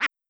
Duck.wav